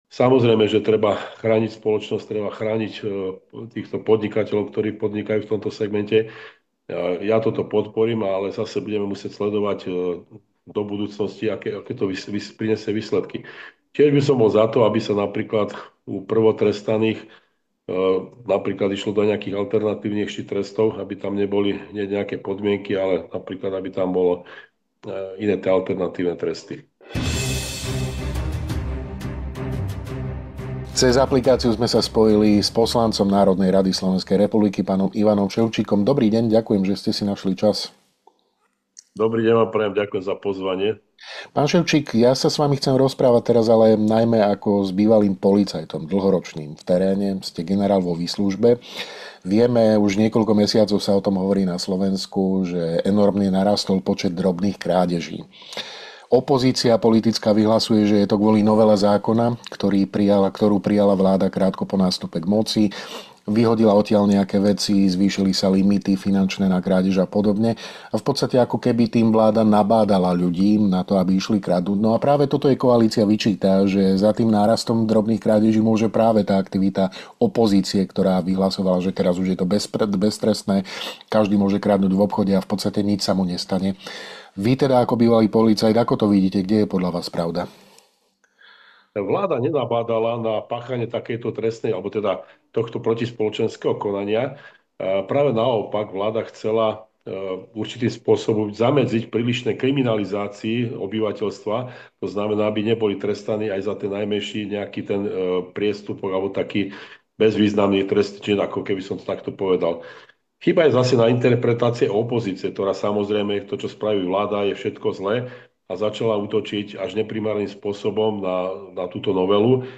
Dozviete sa vo videorozhovore s poslancom NR SR a bývalým vyšetrovateľom, generálom v.v., Ing. Ivanom Ševčíkom.